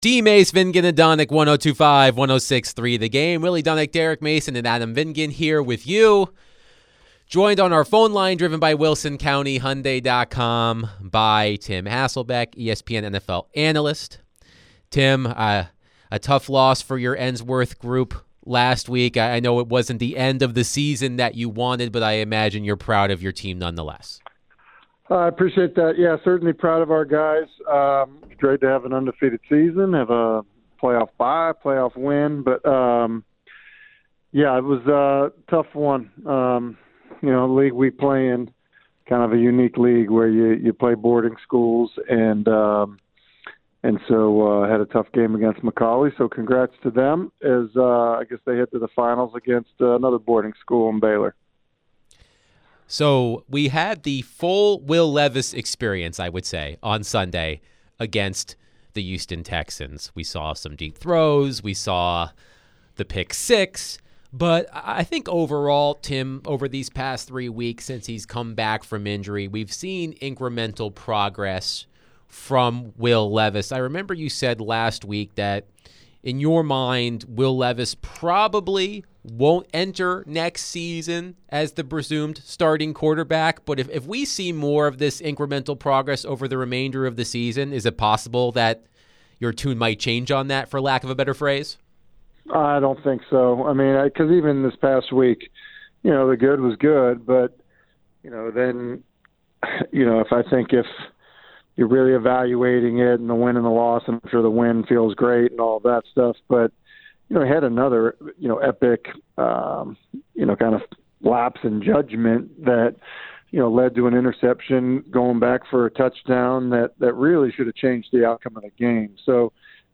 ESPN NFL Analyst Tim Hasselbeck joined the show to share his thoughts on the Titans' win over the Texans on Sunday. What does Tim think of Will Levis's last three games?